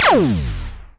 古老的枪声 " G3504枪声响起
描述：枪加载并单击。短片。 这些是20世纪30年代和20世纪30年代原始硝酸盐光学好莱坞声音效果的高质量副本。
我已将它们数字化以便保存，但它们尚未恢复并且有一些噪音。
声道立体声